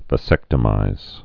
(və-sĕktə-mīz, vā-zĕk-)